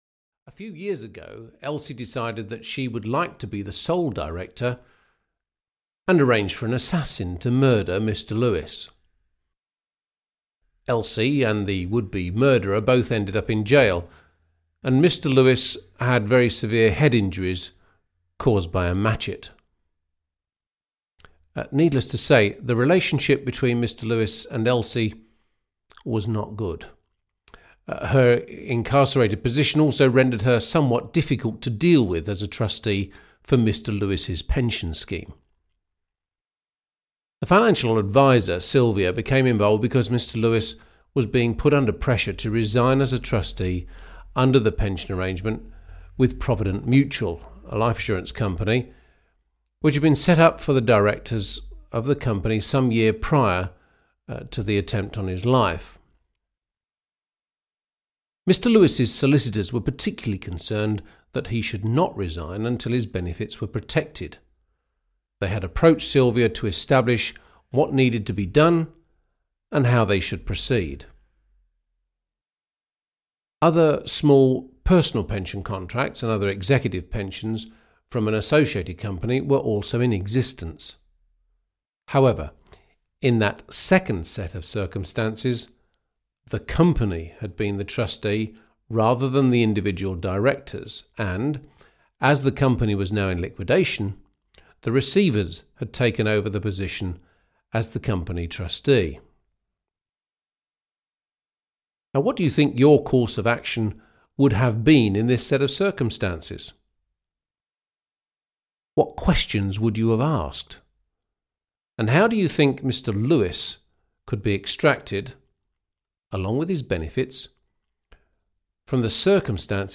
Audio knihaTrusts – A Practical Guide 6 (EN)
Ukázka z knihy